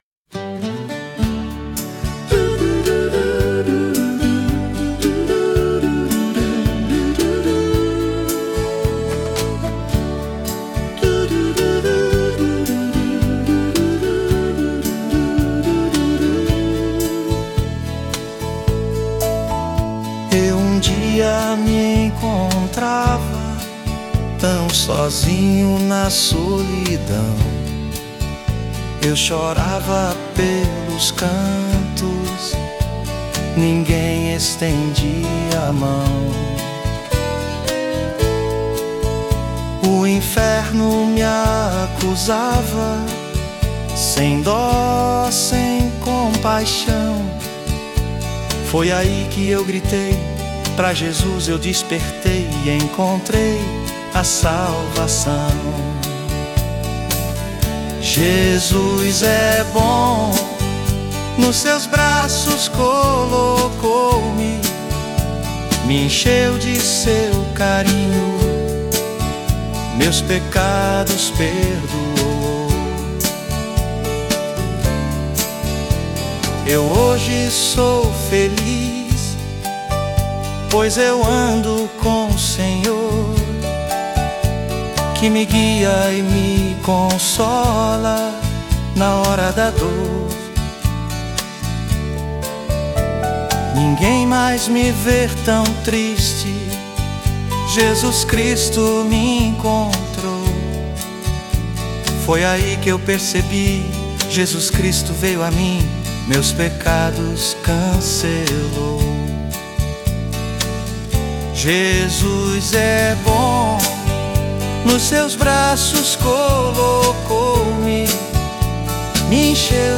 Transforme qualquer ideia em uma música incrível com voz, instrumentos de forma automática
[Vocal Masculino] [Instrumental Intro]